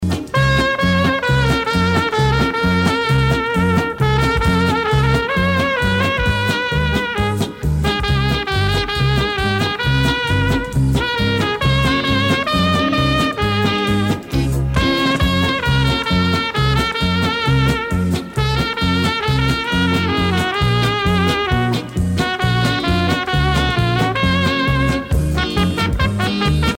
pot-pourri de pièces traditionnelles et populaires
Pièce musicale éditée